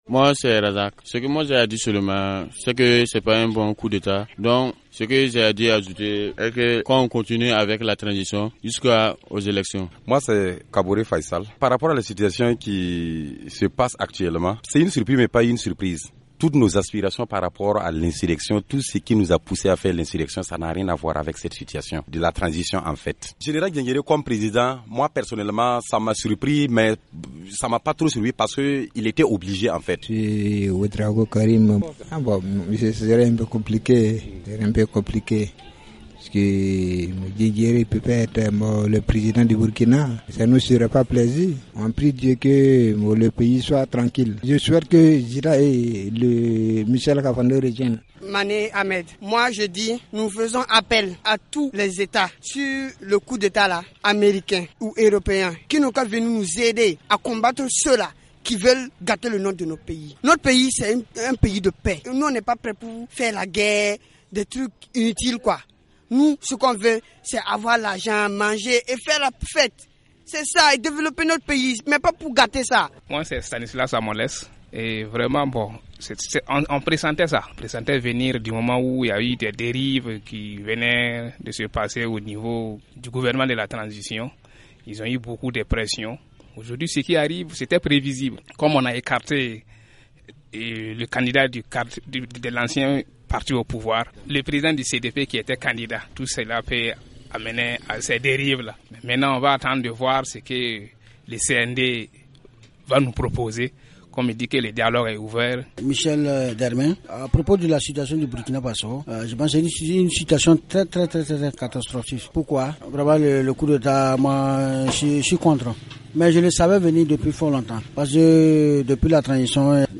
Les réactions de la rue à Ouagadougou